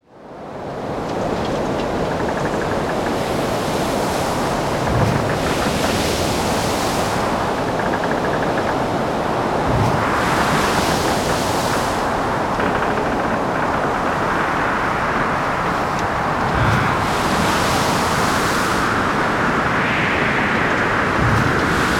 Звуки шторма
Стон мачты, рев ветра, буря моря и гром волн